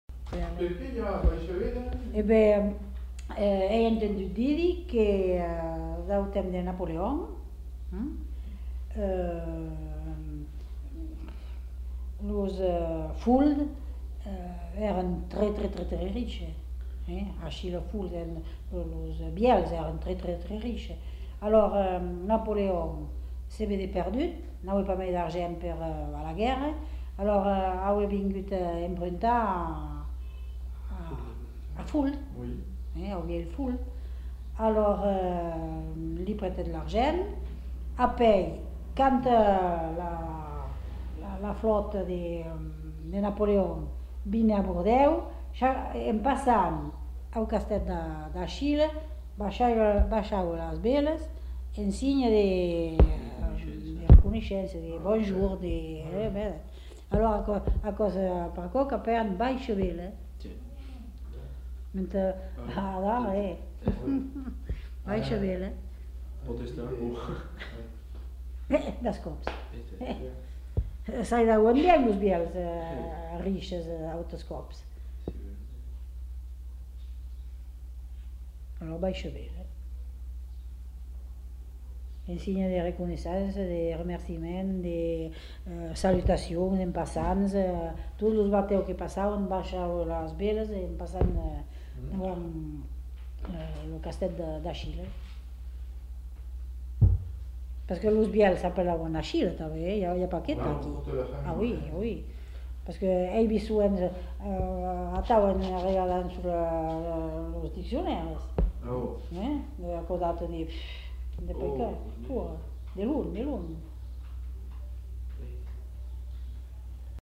Lieu : Moulis-en-Médoc
Genre : conte-légende-récit
Effectif : 1
Type de voix : voix de femme
Production du son : parlé
Classification : récit légendaire